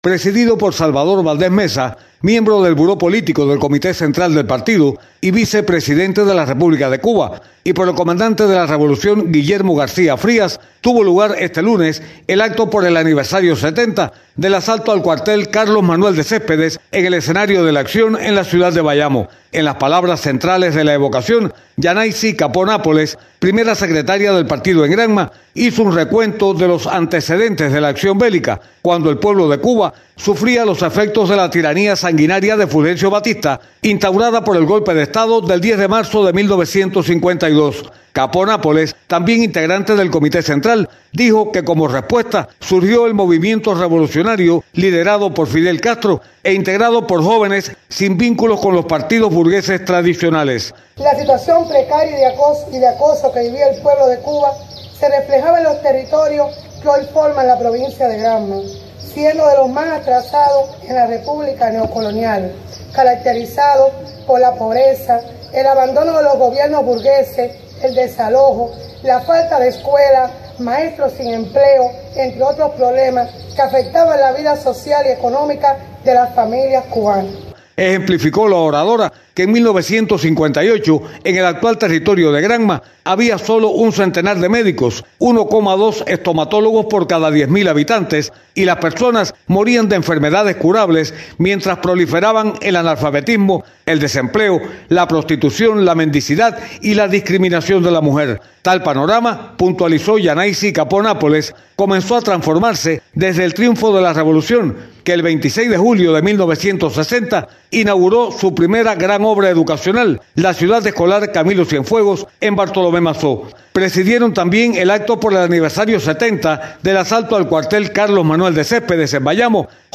El aniversario 70 del asalto al cuartel Carlos Manuel de Céspedes de Bayamo fue recordado, este lunes, con un acto político-cultural en el parque-museo Ñico López de la capital provincial.